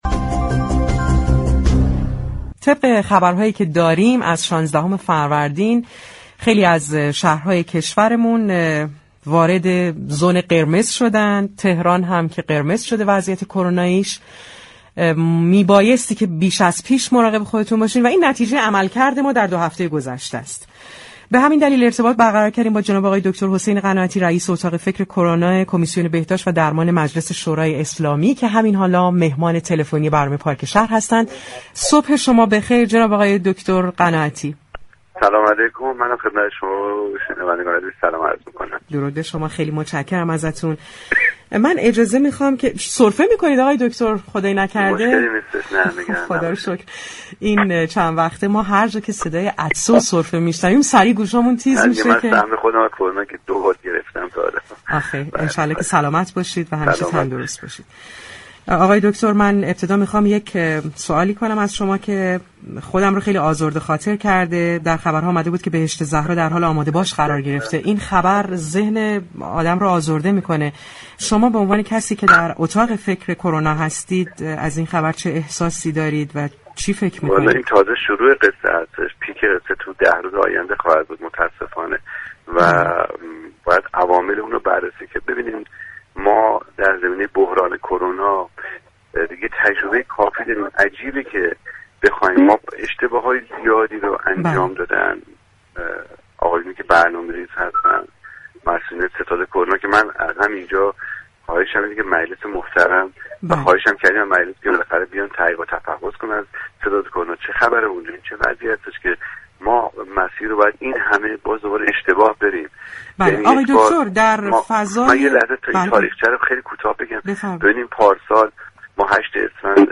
گفتگو كرد